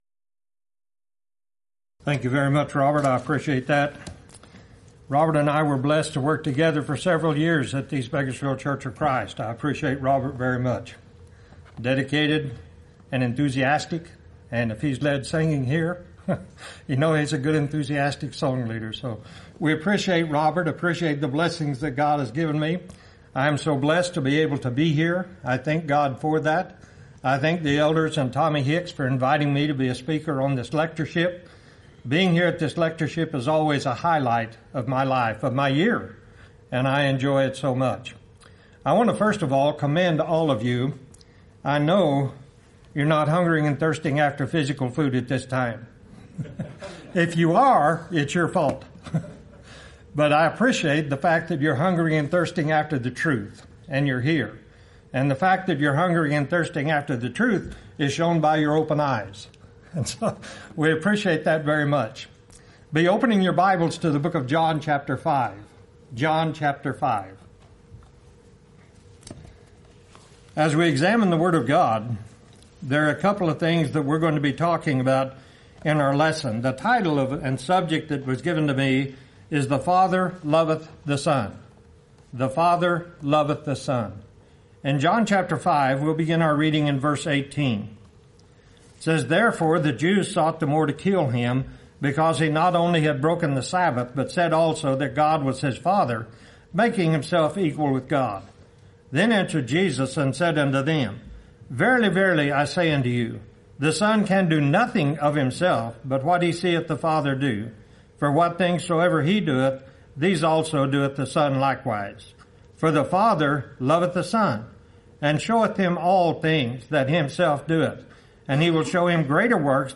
Event: 26th Annual Lubbock Lectures Theme/Title: God is Love